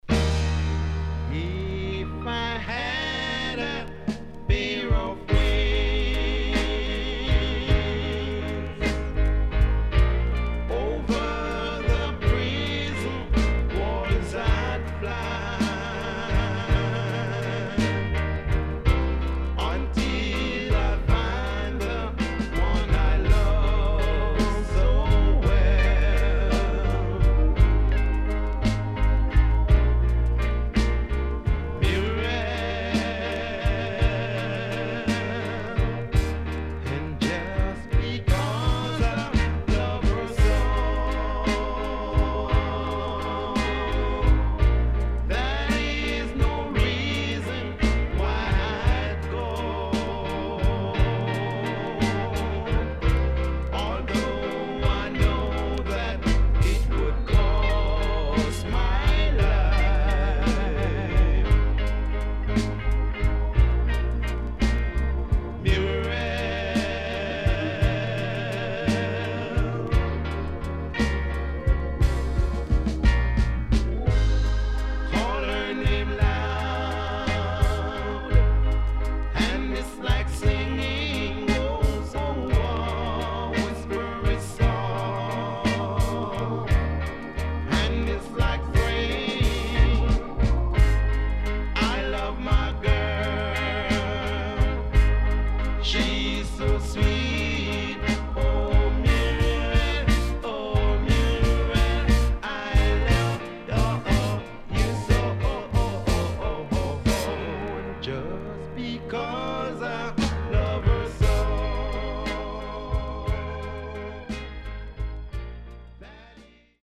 SIDE B:少しノイズ入ります。